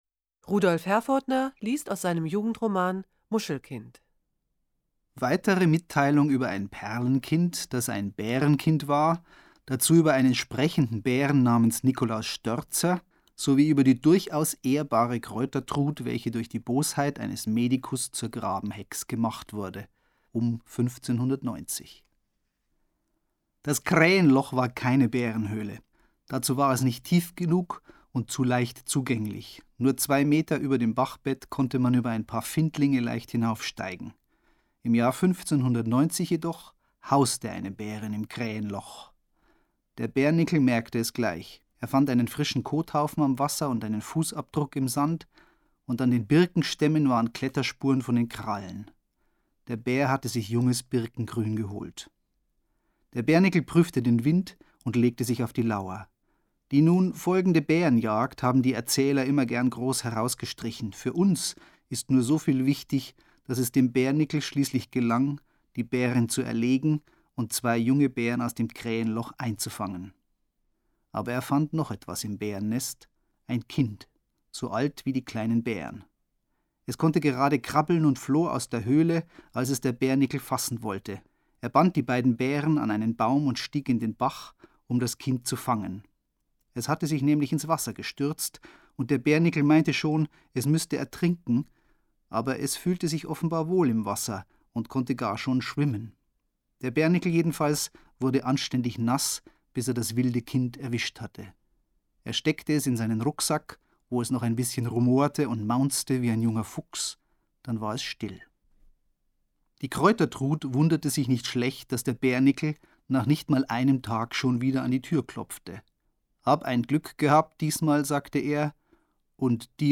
Das Literaturtelefon-Archiv wird in der Monacensia im Hildebrandhaus aufbewahrt. Es umfasst 40 CDs, auf denen insgesamt 573 Lesungen enthalten sind.